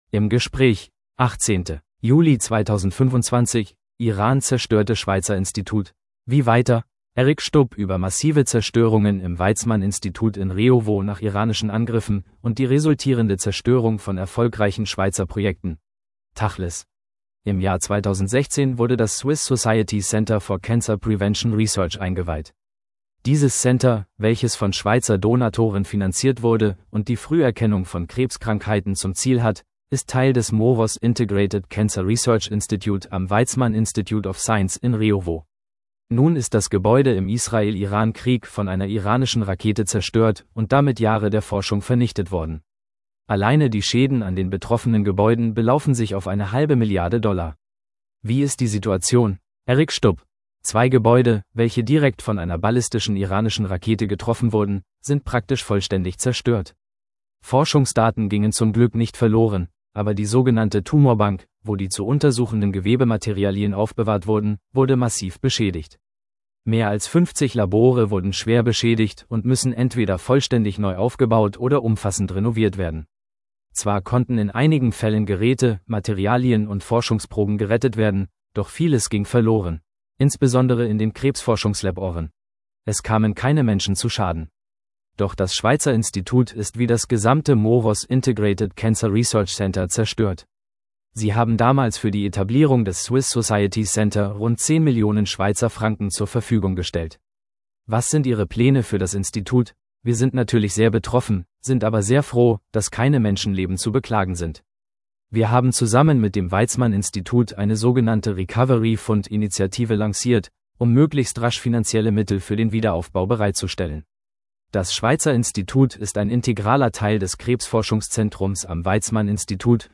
im Gespräch 18.